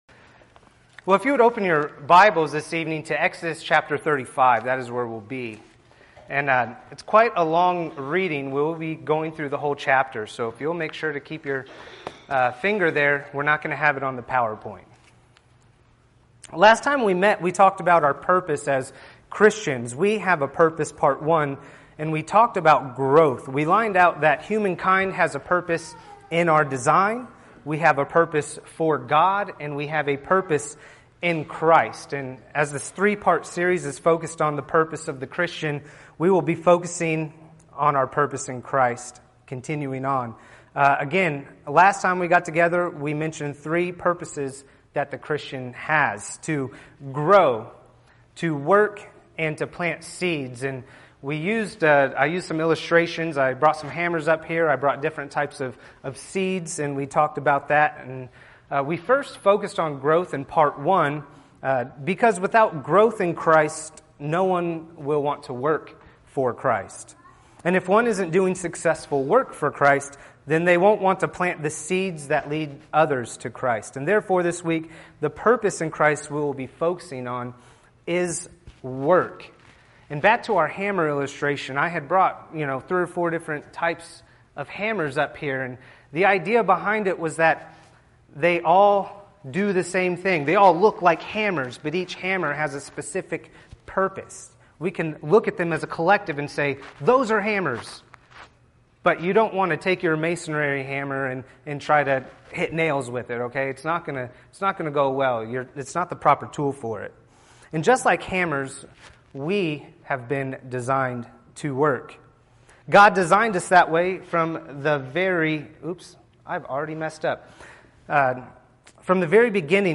Podcasts Videos Series Sermons We Have a Purpose